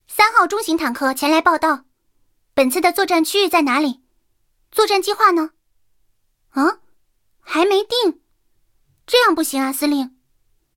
三号登场语音.OGG